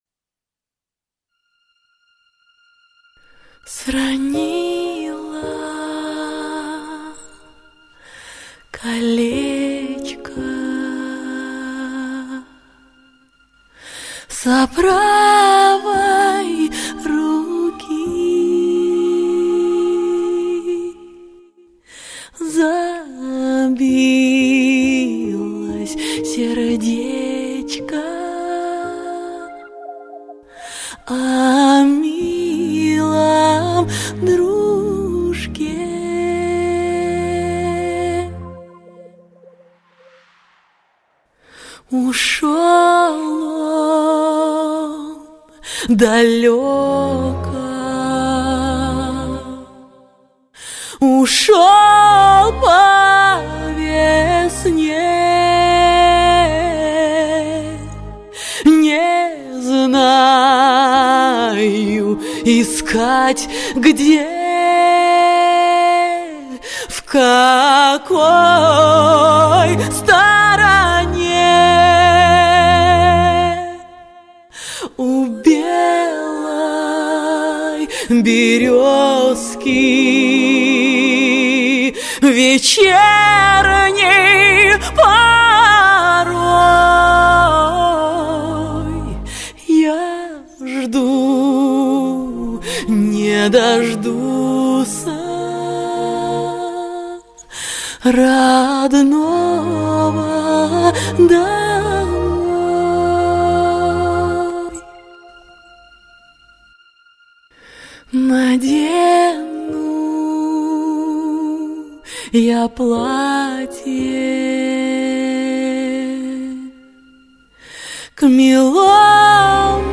(народные баллады)